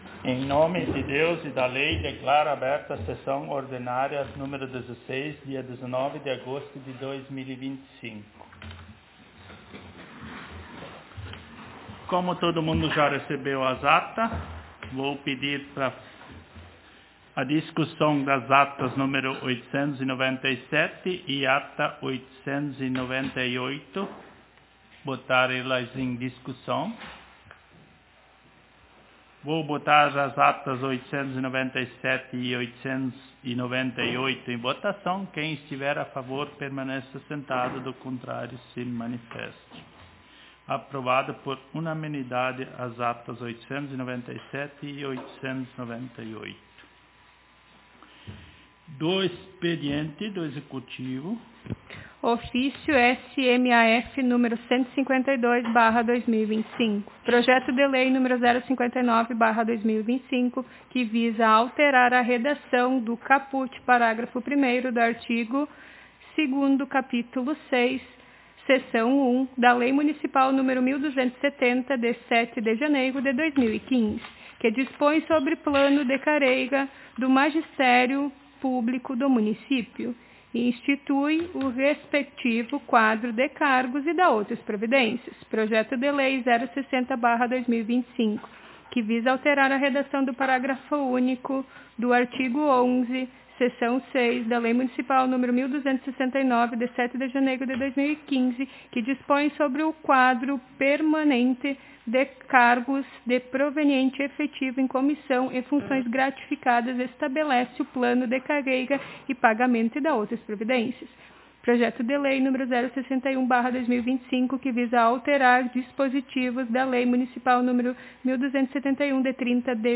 Aos 19 (dezenove) dias do mês de agosto do ano de 2025 (dois mil e vinte e cinco), na Sala de Sessões da Câmara Municipal de Vereadores de Travesseiro/RS, realizou-se a Décima Sexta Sessão Ordinária da Legislatura 2025-2028.